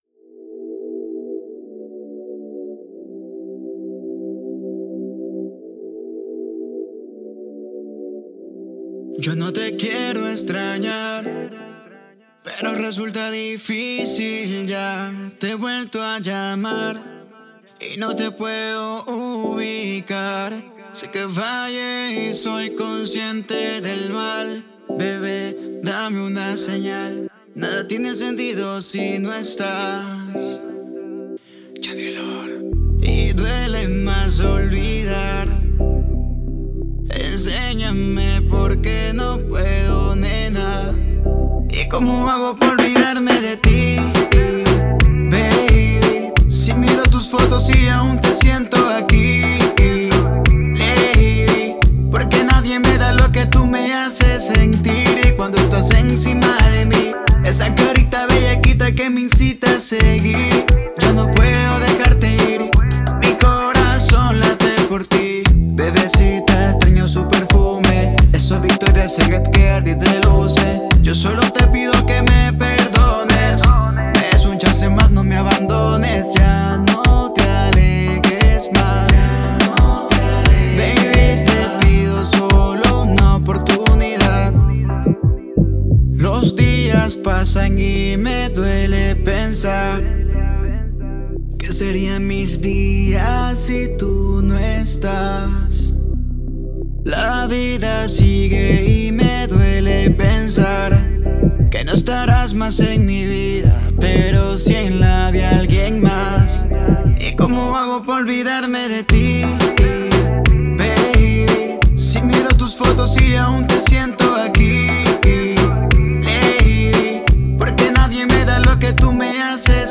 Su estilo musical es el Pop urbano, reggae y la balada.